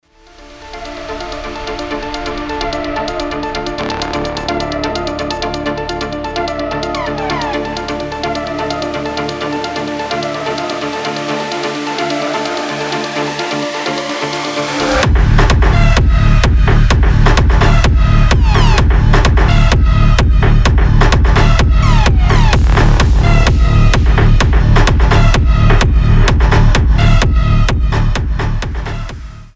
• Качество: 320, Stereo
мощные
Electronic
EDM
Техно
Hard techno